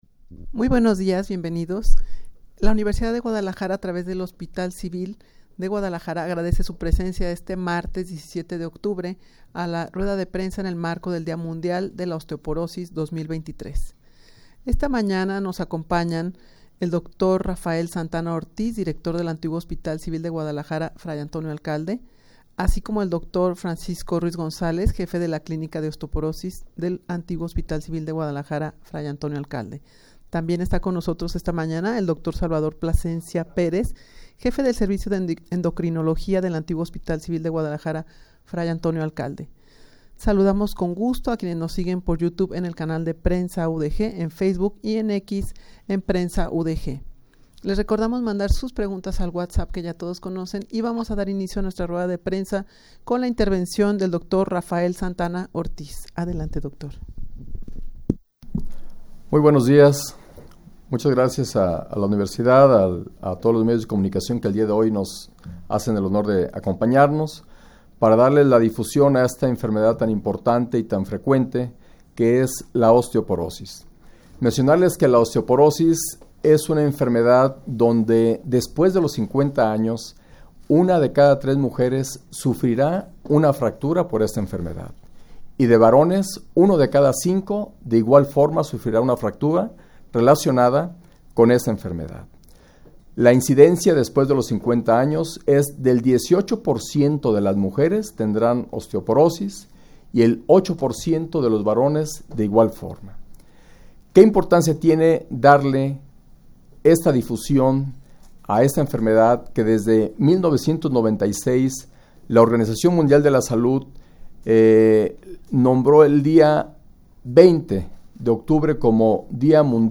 Audio de la Rueda de Prensa
rueda-de-prensa-en-el-marco-del-dia-mundial-de-la-osteoporosis-2023.mp3